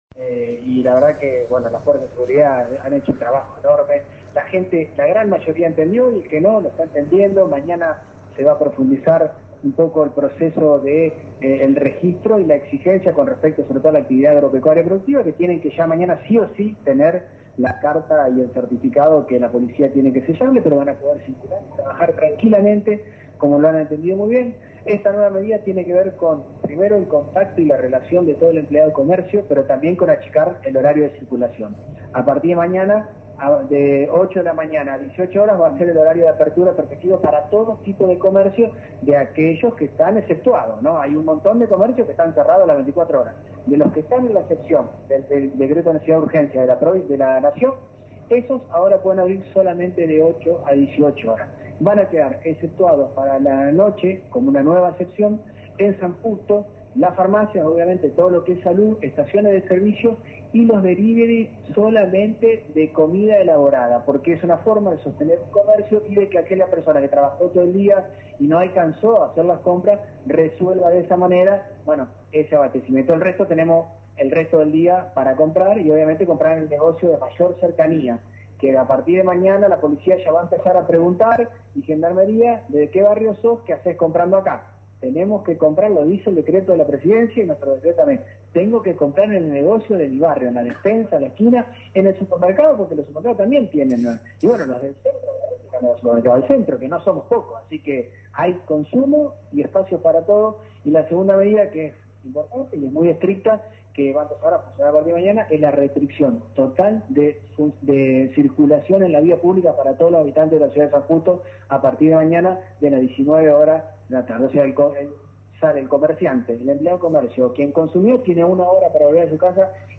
Como explicó el intendente en conferencia con respecto a las novedades del COVID-19, «Esto es minuto a minuto» y es por eso que el titular del ejecutivo Municipal ha firmado un nuevo decreto con medidas a adoptar a partir de este lunes 23.